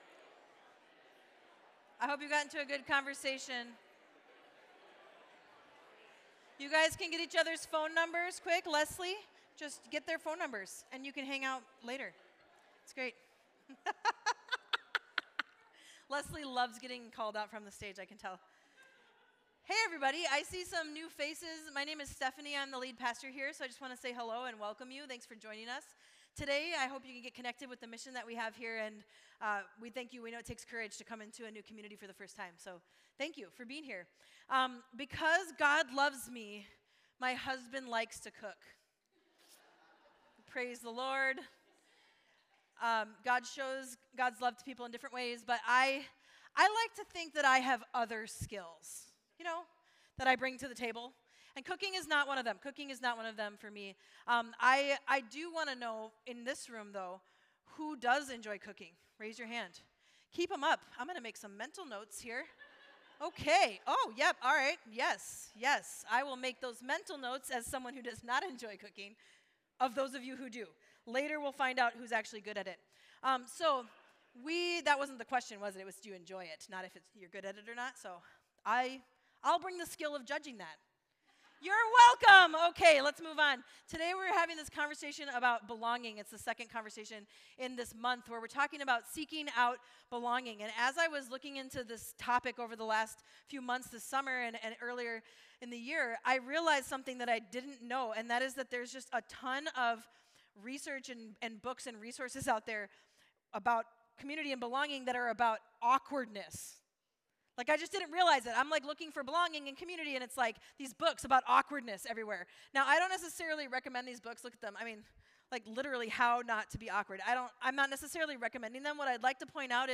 Mill City Church Sermons Seek Out Belonging: Body of Christ Aug 13 2023 | 00:39:16 1x 00:00 / 00:39:16 Subscribe Share RSS Feed Share Link Embed